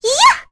Annette-Vox_Attack4.wav